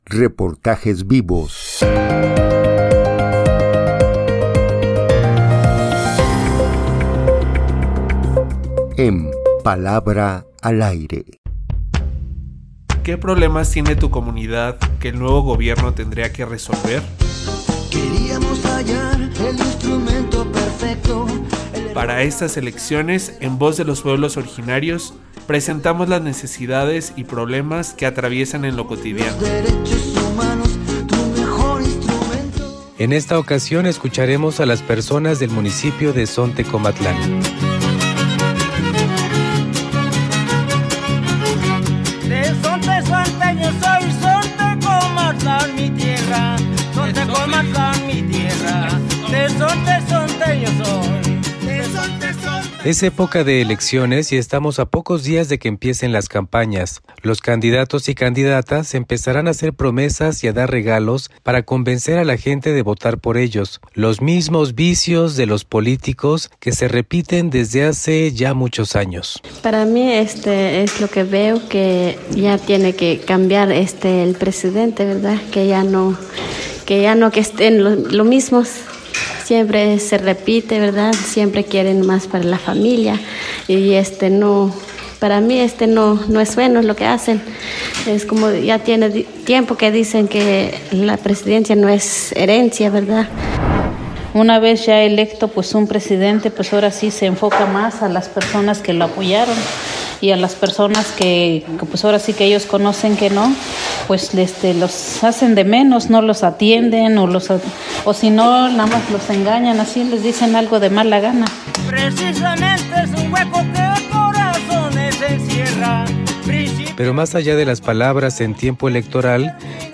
Esta es la opinión de la gente en Zontecomatlán de López y Fuentes.
Reportaje-Elecciones-Zonte.mp3